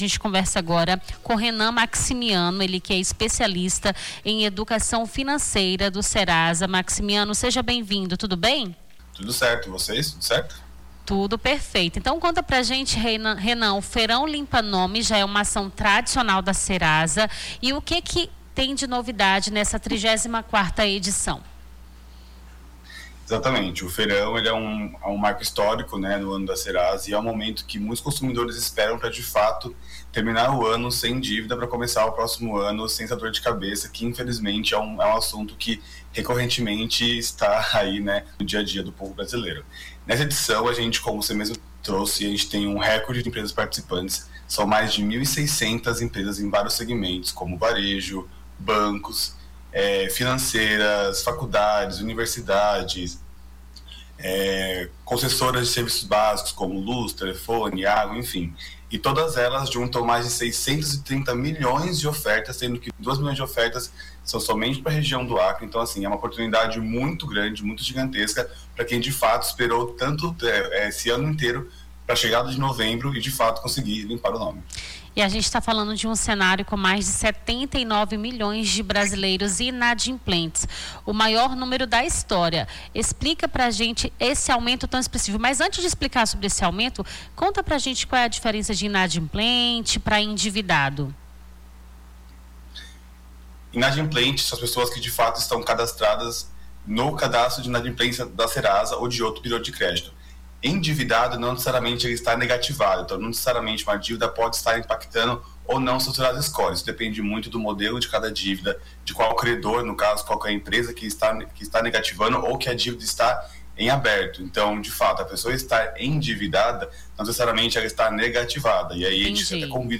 Baixar Esta Trilha Nome do Artista - CENSURA - ENTREVISTA (FEIRAO LIMPA NOME) 04-11-25.mp3 Foto: Reprodução Facebook Twitter LinkedIn Whatsapp Whatsapp Tópicos Rio Branco Acre Serasa Correios Feirão Limpa Nome